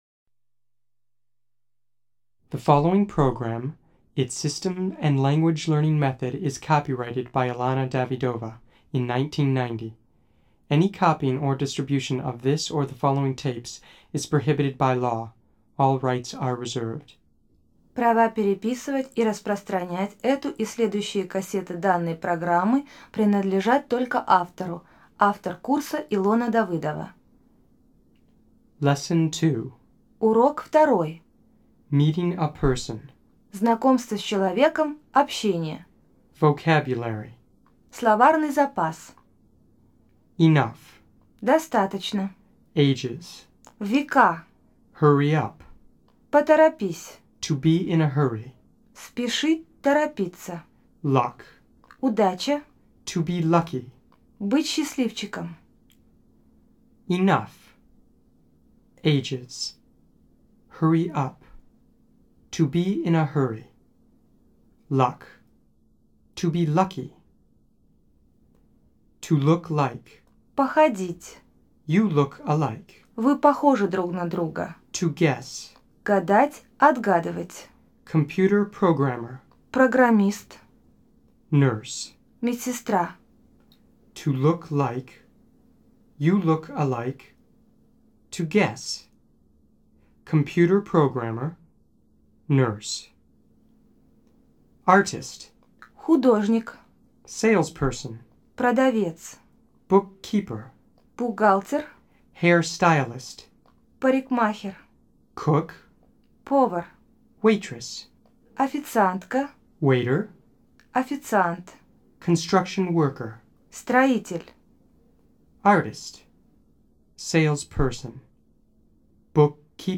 Аудиокнига Разговорно-бытовой английский. Курс 1. Диск 2. Знакомство с человеком | Библиотека аудиокниг